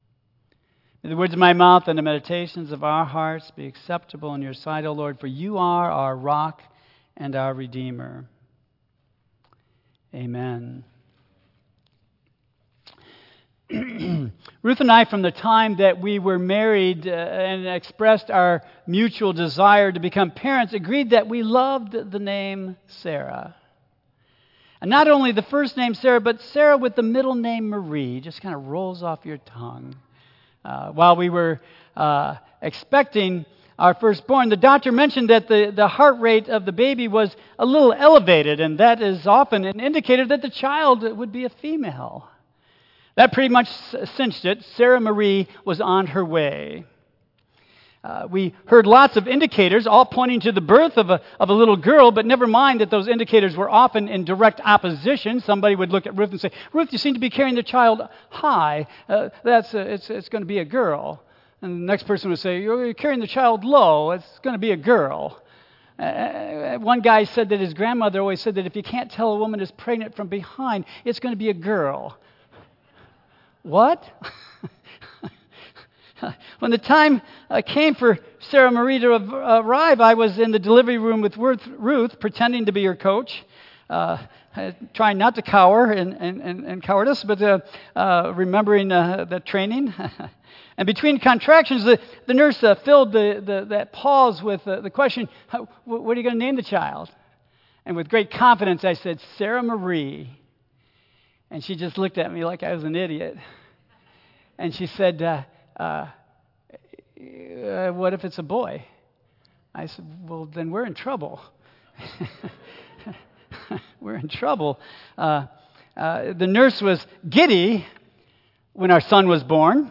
Tagged with Michigan , Sermon , Waterford Central United Methodist Church , Worship Audio (MP3) 7 MB Previous Salome - Mother of the Sons of Thunder Next Anna - How Long Can You Wait?